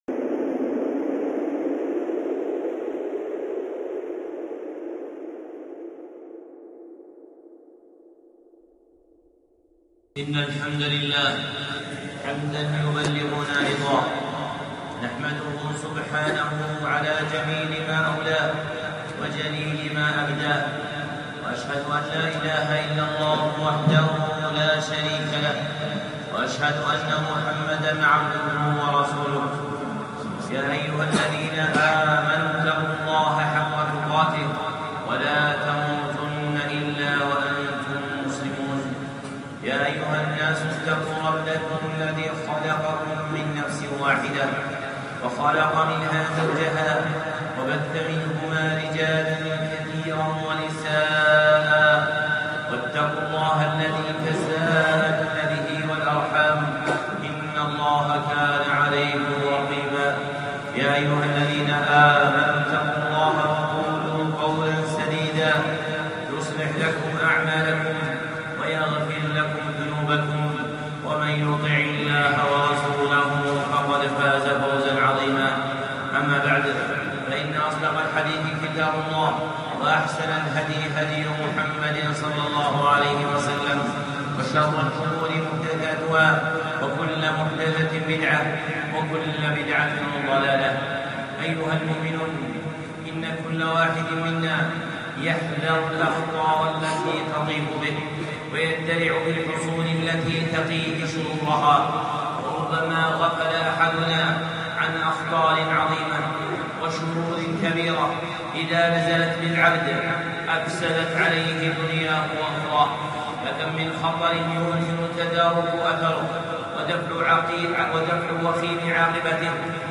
خطبة (الخطر العظيم) الشيخ صالح العصيمي